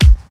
Mid Heavy Kickdrum Sound B Key 58.wav
Royality free bass drum single shot tuned to the B note. Loudest frequency: 423Hz
mid-heavy-kickdrum-sound-b-key-58-LRW.mp3